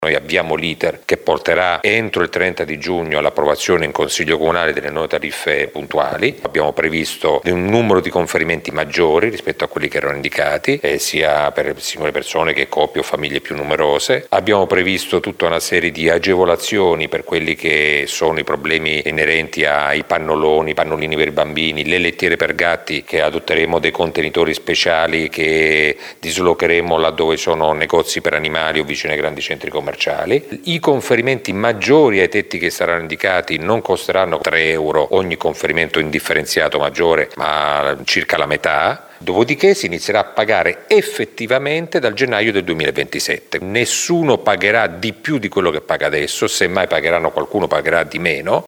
Lo ha spiegato il sindaco di Modena Massimo Mezzetti…